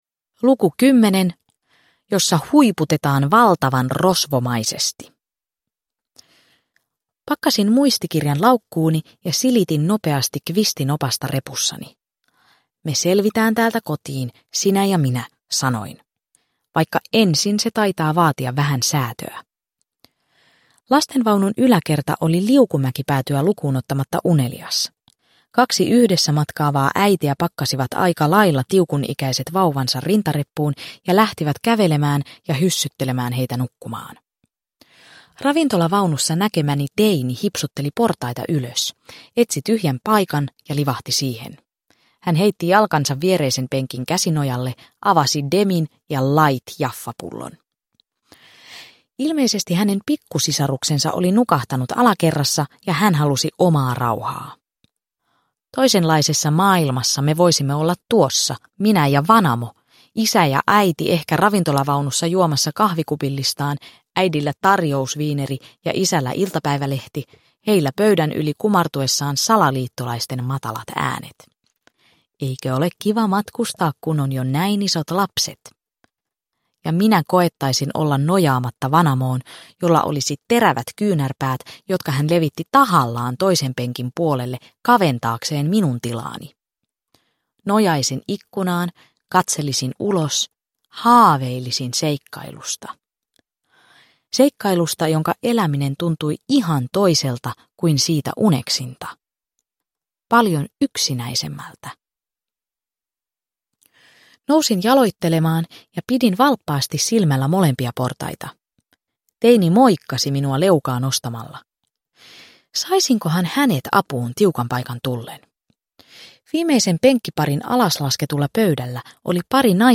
Me Rosvolat ja Iso-Hemmin arkku – Ljudbok – Laddas ner